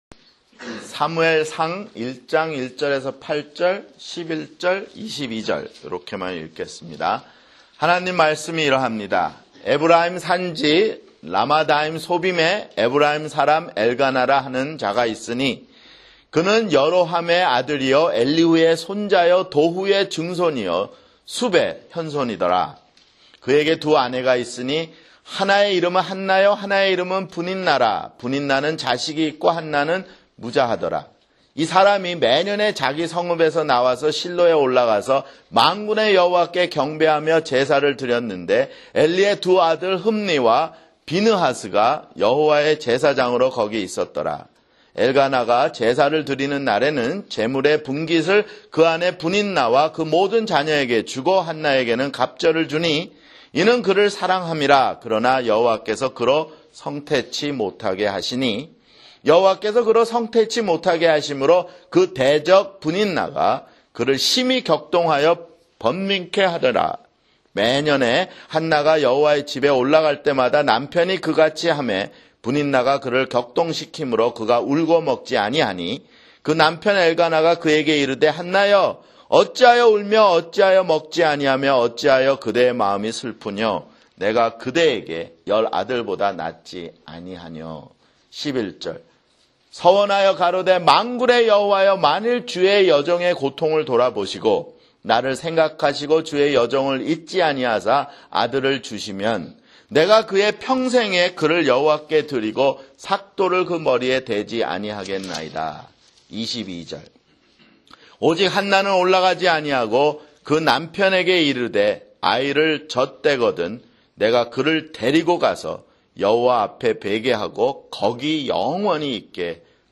[주일설교] 사무엘상 (6)